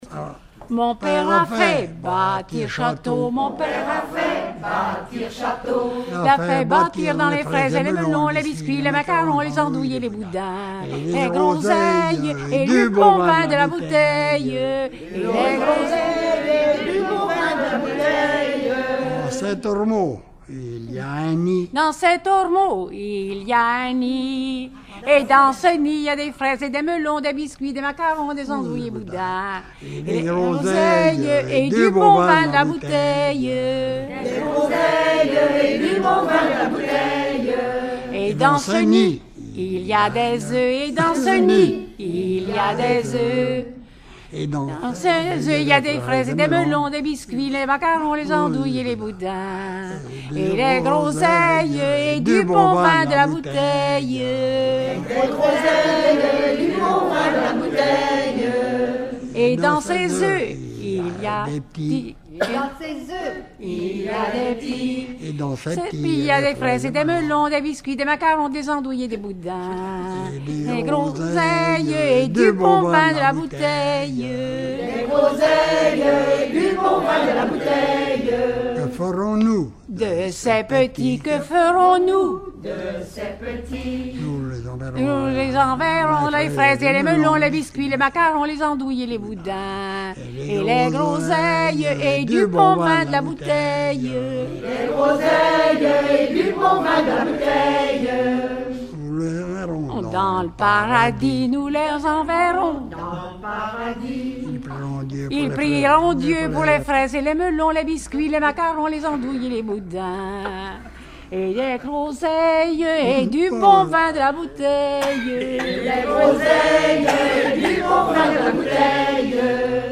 Genre laisse
Regroupement de chanteurs du canton
Pièce musicale inédite